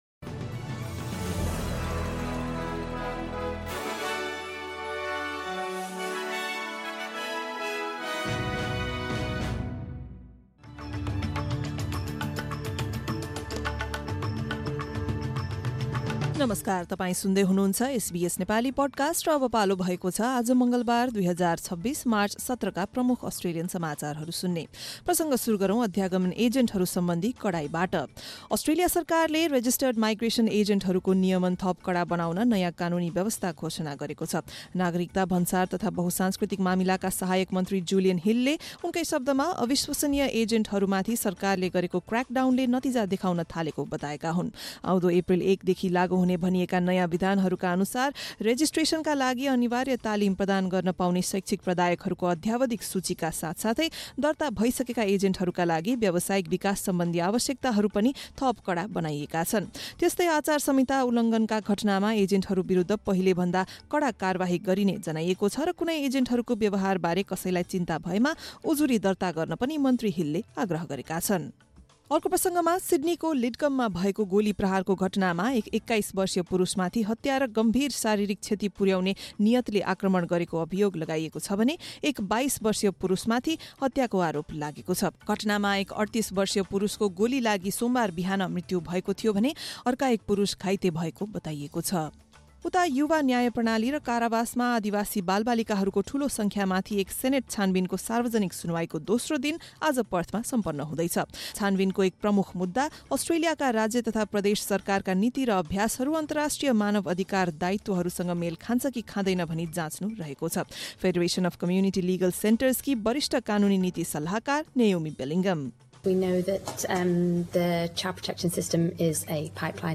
SBS Nepali Australian News Headlines: Tuesday, 17 March 2026